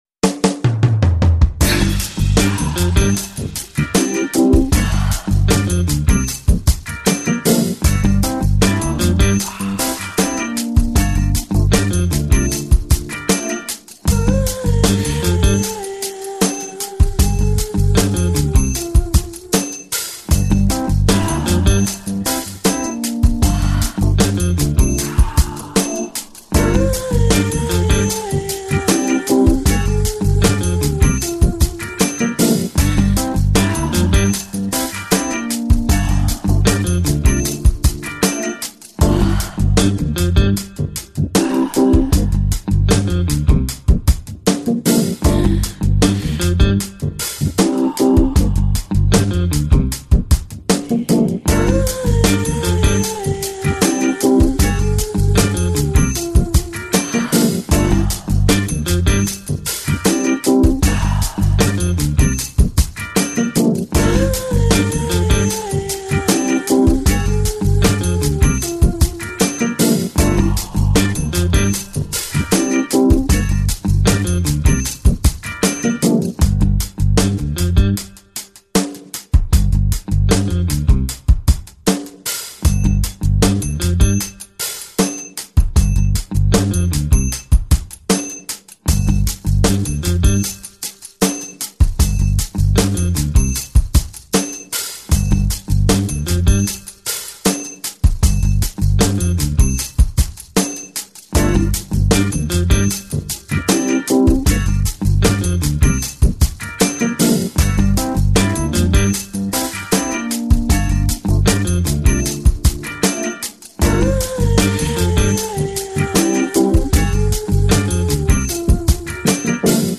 это зажигательный трек в жанре поп с элементами R&B